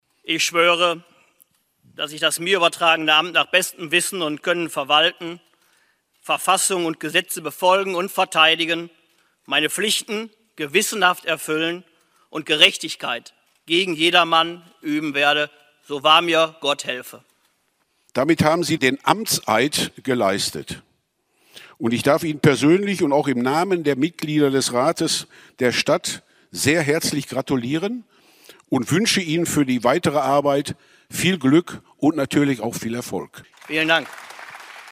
Oberbürgermeister Thomas Kufen ist in der Grugahalle für seine zweite Amtszeit vereidigt worden. In der ersten Sitzung des neu gewählten Stadtrates hat er am Mittwoch (04.11.2020) feierlich den Amtseid gesprochen und danach eine gut zehnminütige Rede gehalten.
amtseid-kufen.mp3